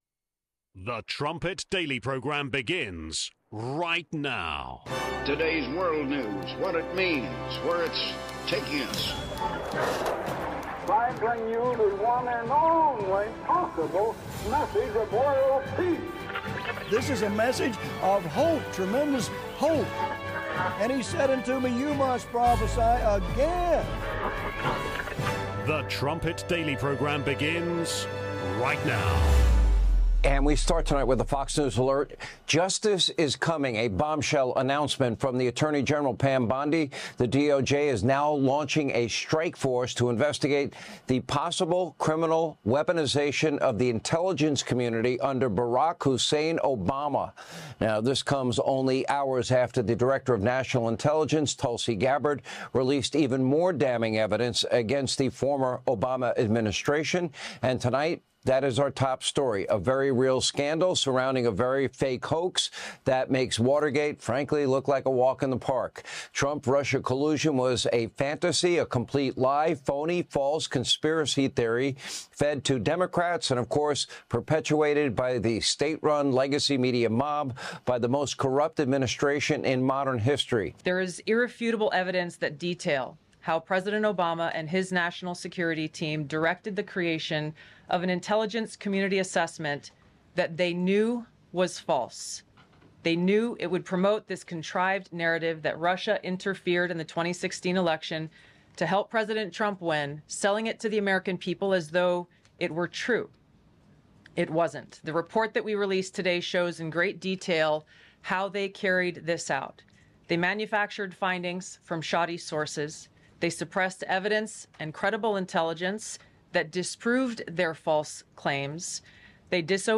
34:20 SEP Camper Interview (23 minutes)
Ten Summer Educational Program campers describe their camp experience.